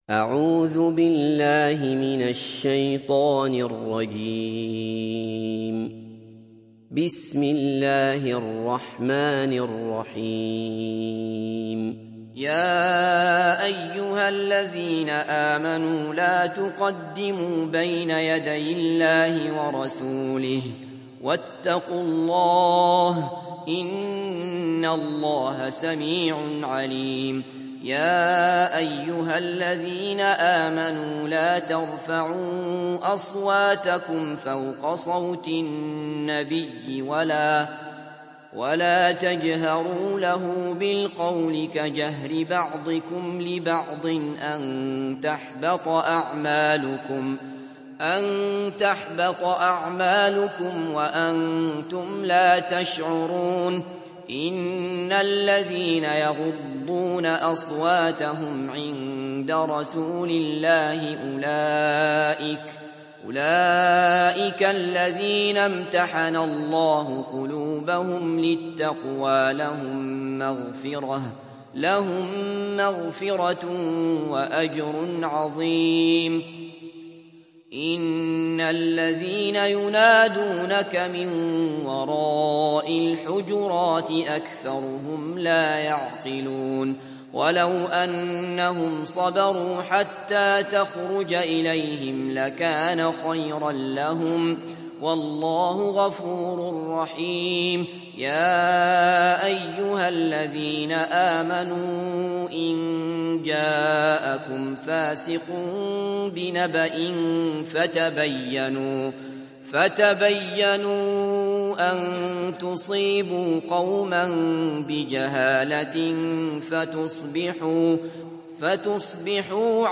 Surat Al Hujurat Download mp3 Abdullah Basfar Riwayat Hafs dari Asim, Download Quran dan mendengarkan mp3 tautan langsung penuh